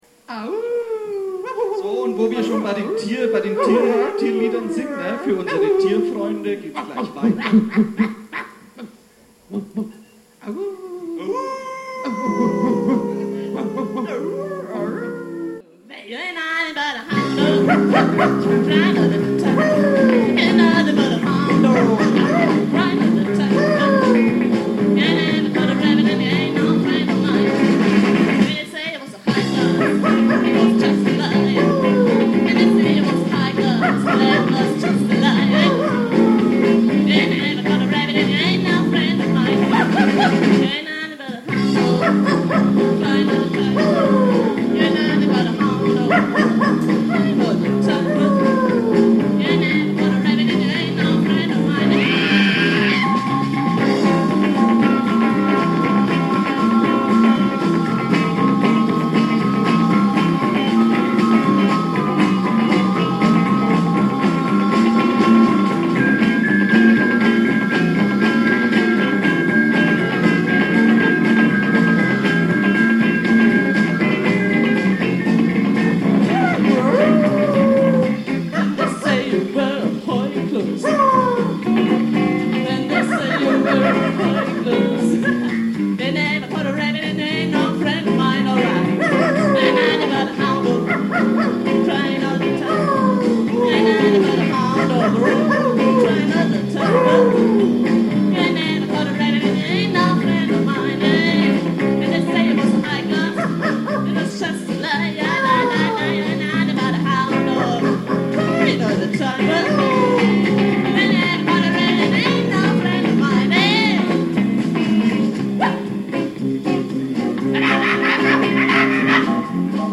Rock 'n Roll